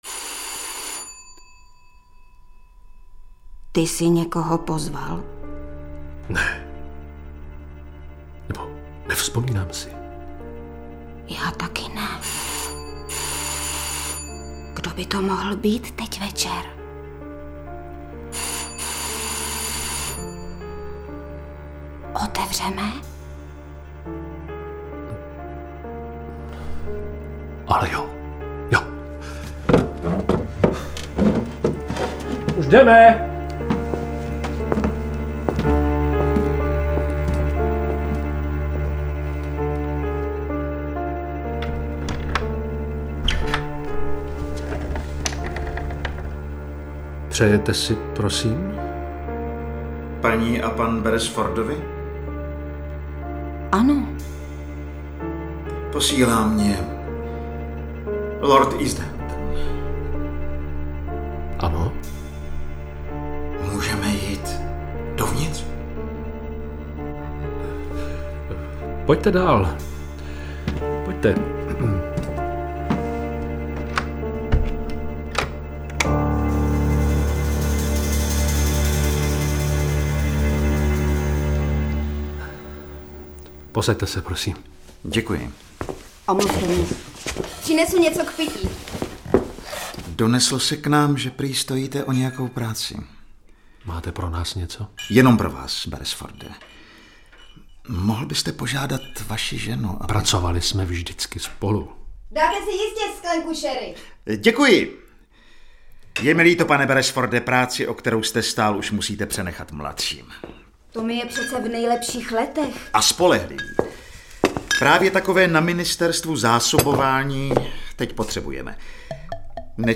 Audioknihy
Čte Zuzana Kajnarová